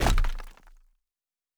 Stone 13.wav